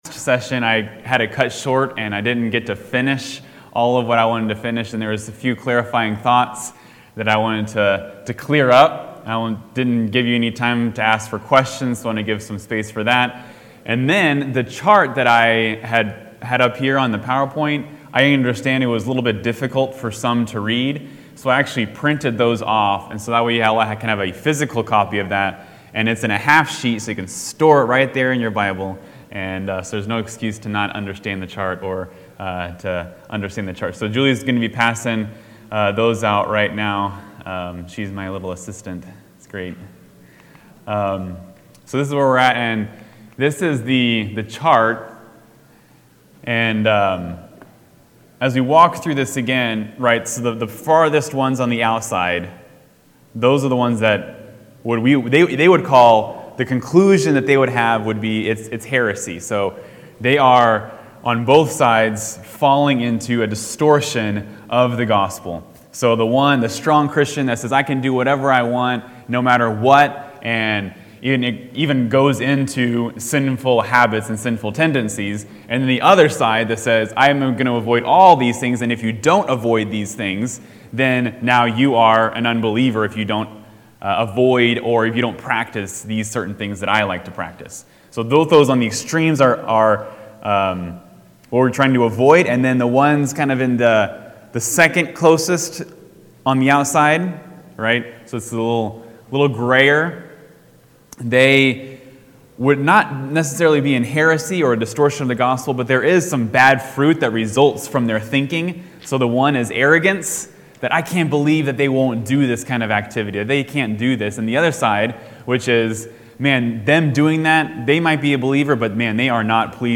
Adult Sunday School class